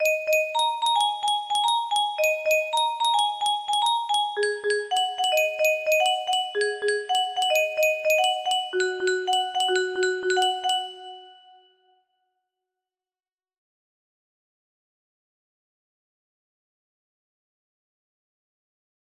I improvised a bit towards the end but its roughly right.